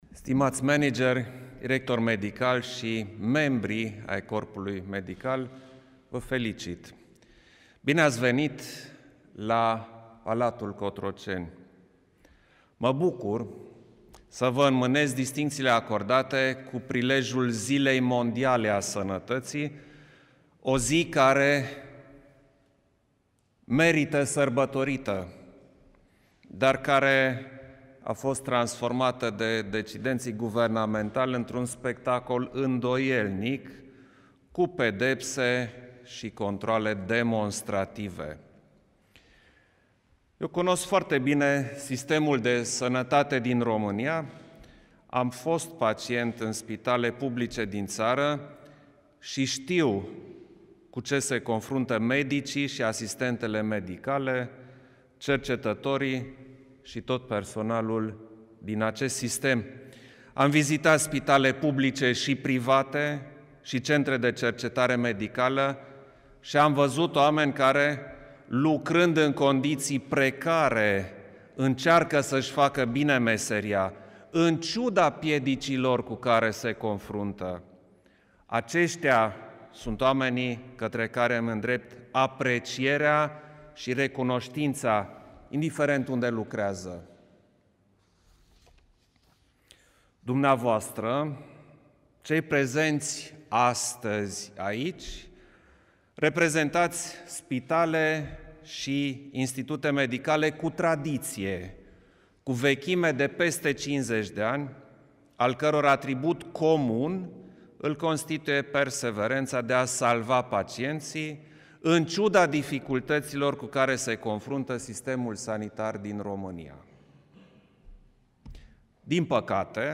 Președintele României, Klaus Iohannis, a decorat, marţi, Spitalul Județean de Urgenţă „Sfântul Ioan cel Nou” Suceava cu Ordinul „Meritul Sanitar” în grad de Cavaler, în cadrul unei ceremonii care a avut loc la Palatul Cotroceni.
Alocuţiunea rostită la eveniment de către preşedintele Iohannis o puteţi asculta mai jos: